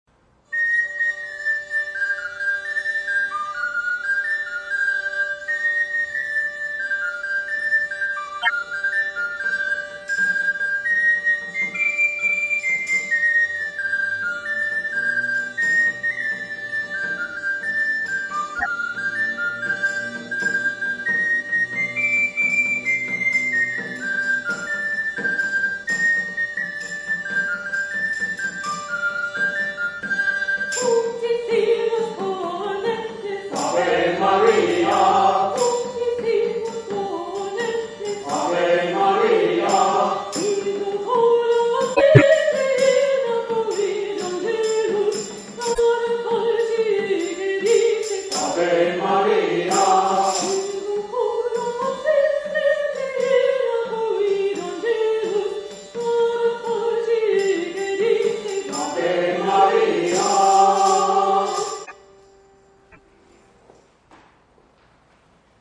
Click to download MP3 recordings of past concerts.